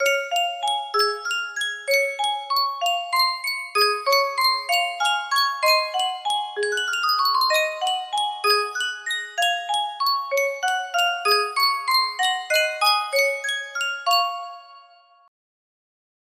Sankyo Music Box - Over the Waves DN music box melody
Full range 60